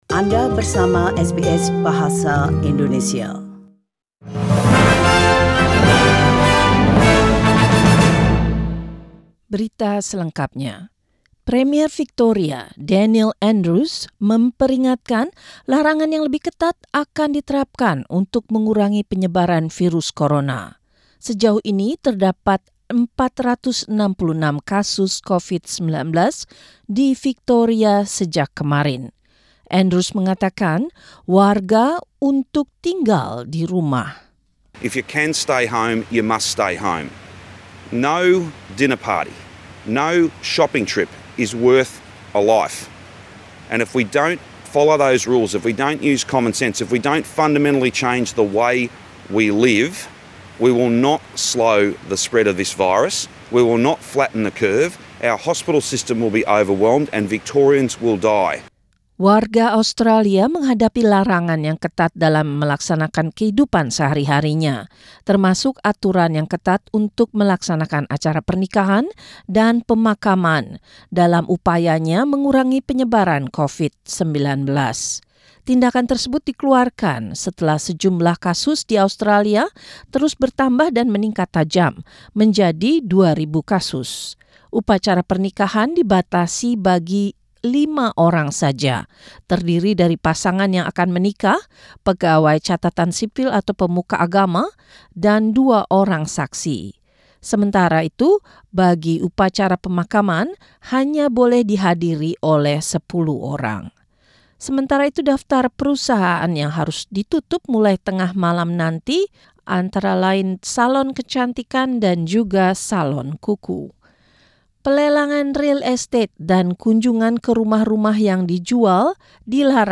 News in Indonesian - 25 March 2020